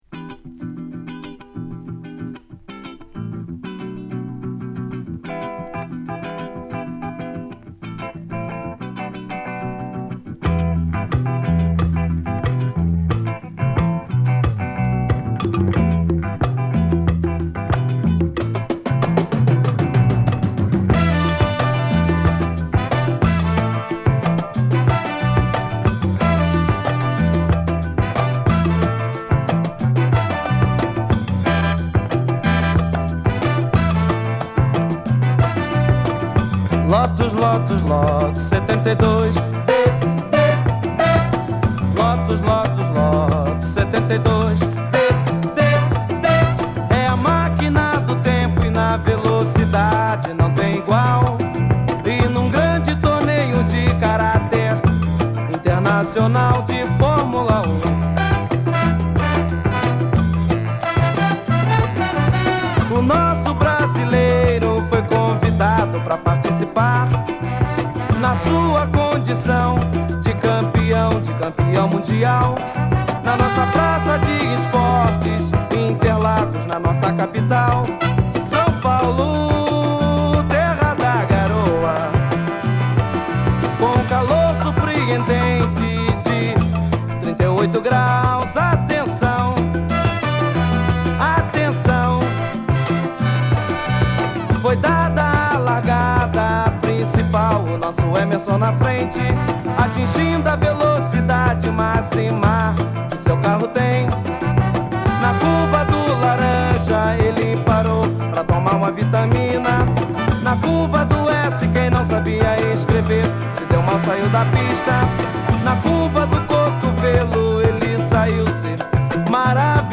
テンポを上げたヴァージョンでコチラも勿論素晴らしい！！